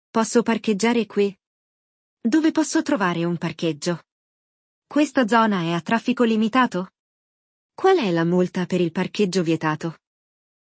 Vamos acompanhar uma conversa entre um motorista e um guarda, onde aprenderemos não apenas sobre as regras de trânsito, mas também vocabulário prático e expressões essenciais para circular pela Itália com confiança.